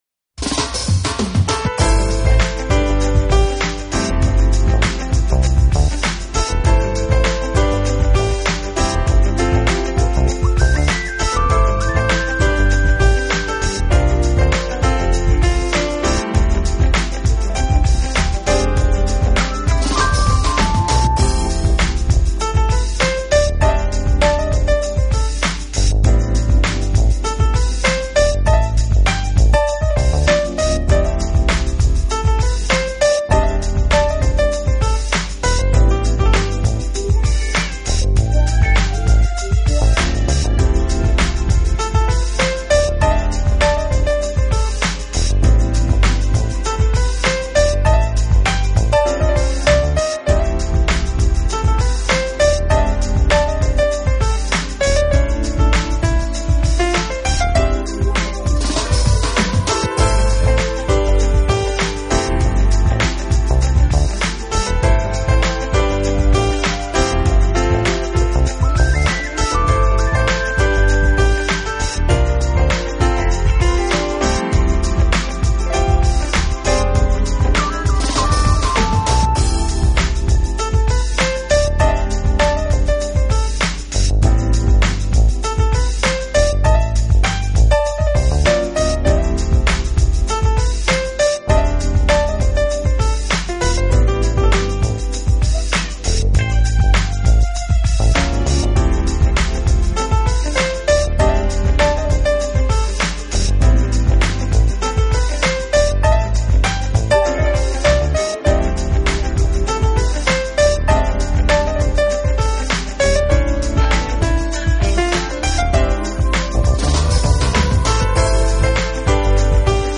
Genre: Jazz
keeps the rhythm relaxed so that